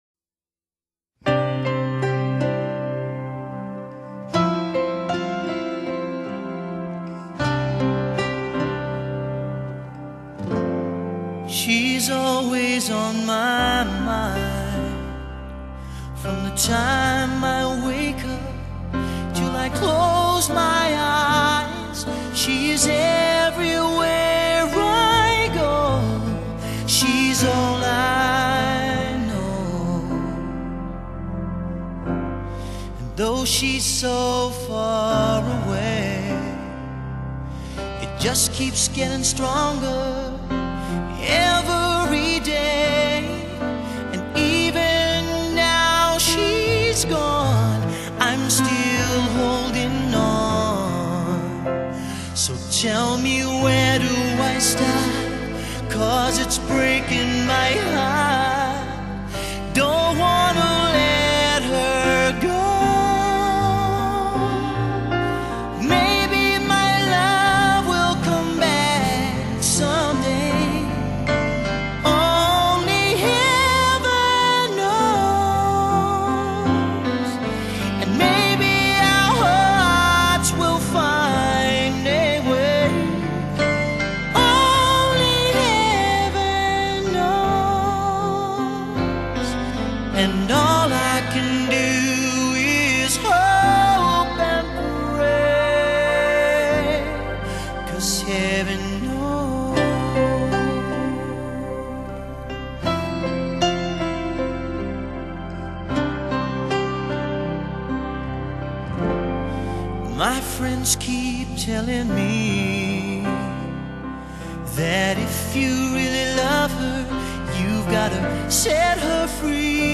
裡面收錄都是經典的原唱版的情歌